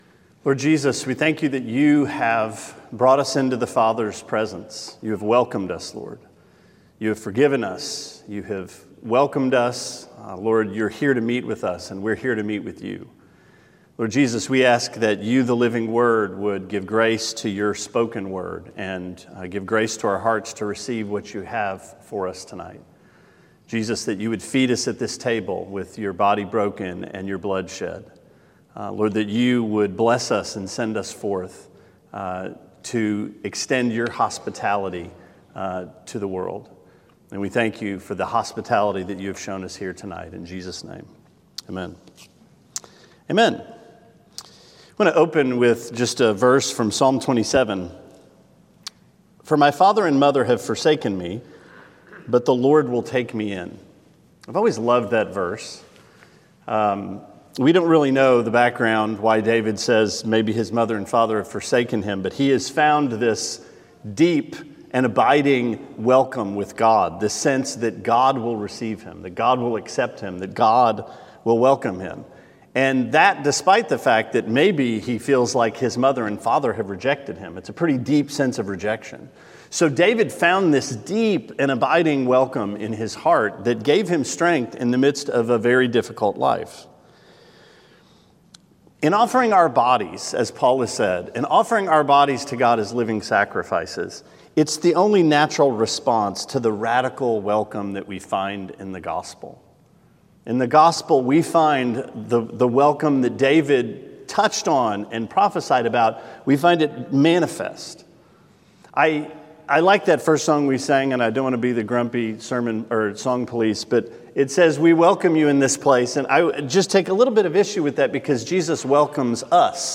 Sermon 5/2: Romans 14-15.13 - Welcome One Another